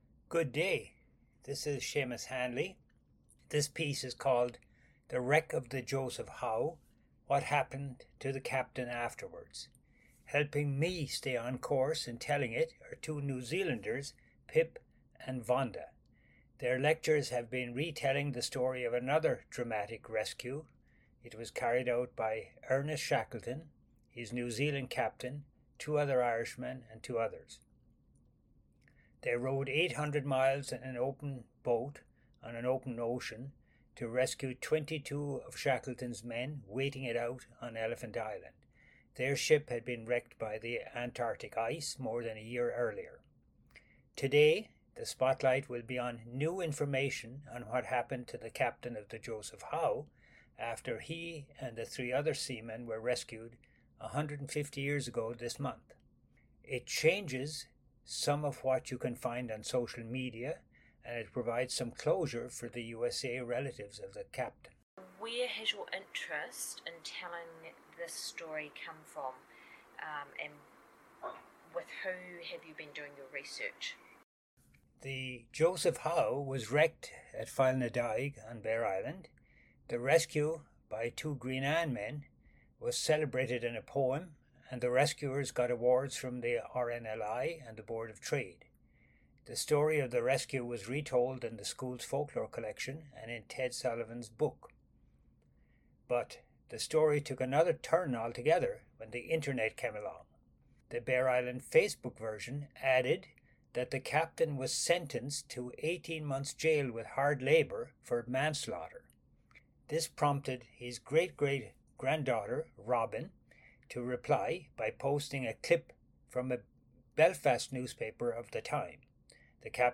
Bere Island Community Radio, Feb 15, 2026